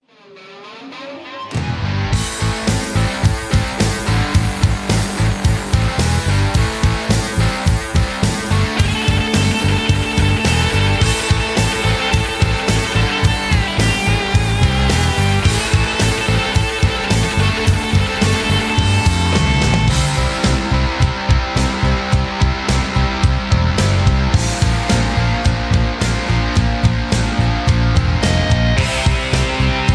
karaoke, rock